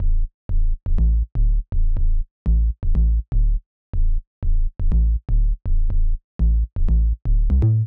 ORG Bass Riff A-D-C.wav